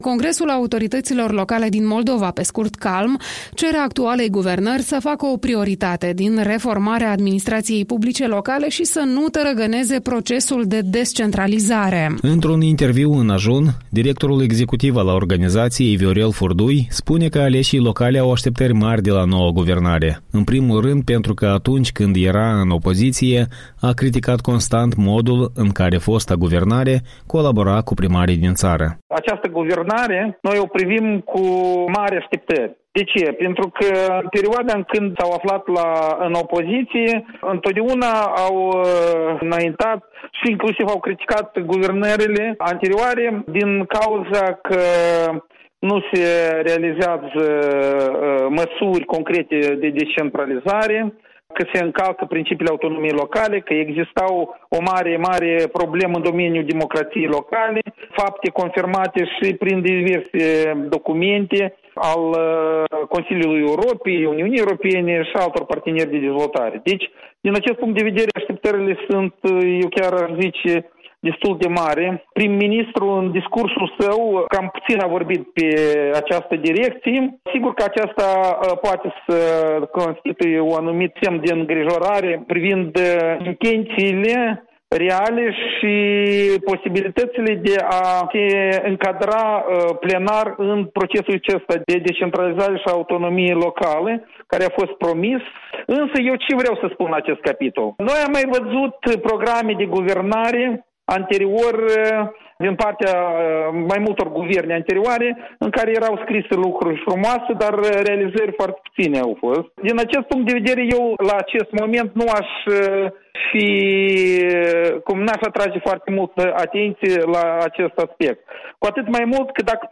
Interviul matinal despre autonomia locală și descentralizarea resurselor